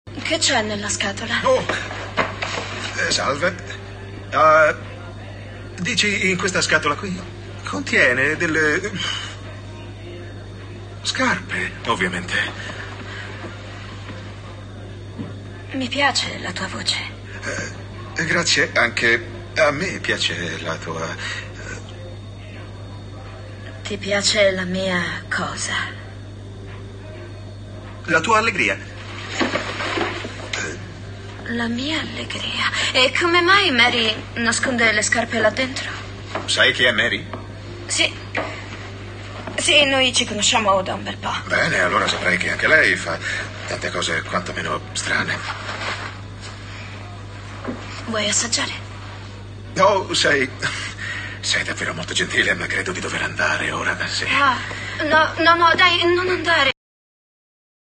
nel telefilm "Mary Kills People", in cui doppia Richard Short.